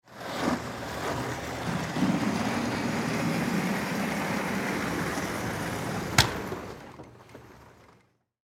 На этой странице собраны звуки традиционных японских ширм – редкие и атмосферные аудиозаписи.
Отодвигаем напольную ширму в сторону